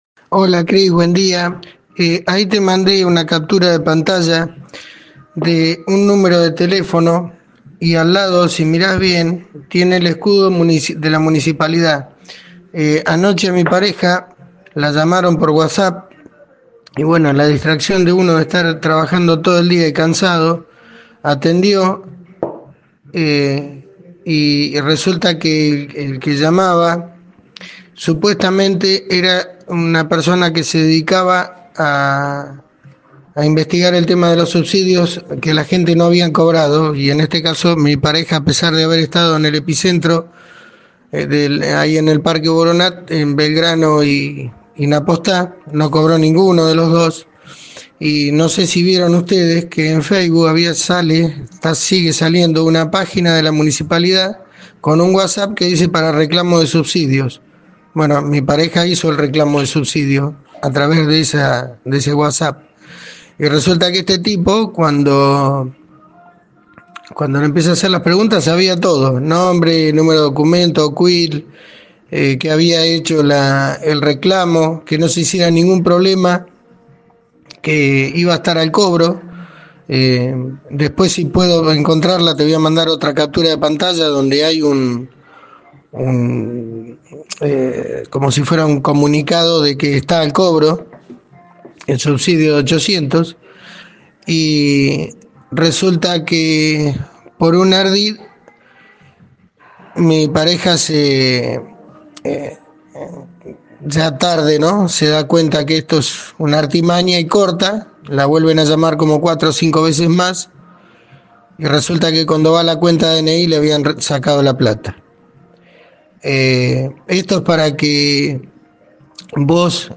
ESCUCHAR EL TESTIMONIO DEL OYENTE: